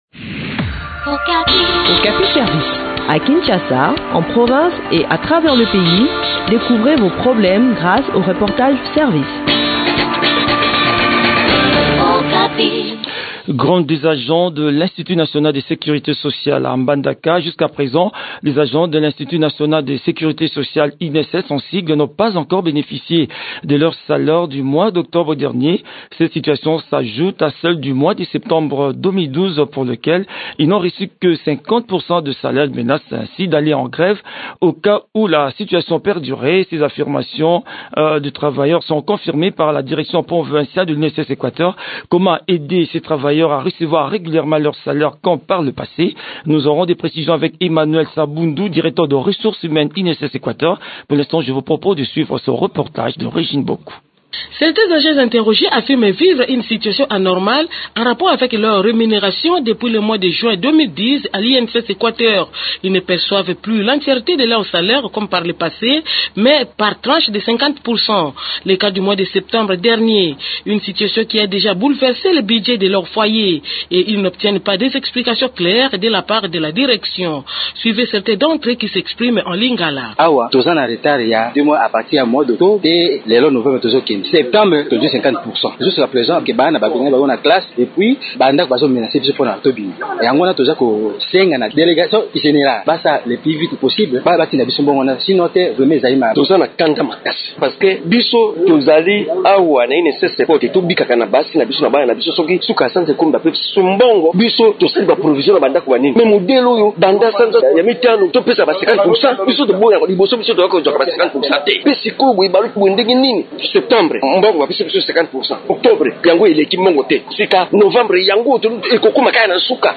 Le point de la situation sur le terrain dans cet entretien